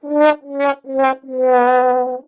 fail-trombone-wah-wah-wah-sound-effect.mp3